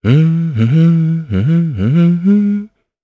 Evan-Vox_Hum_jp.wav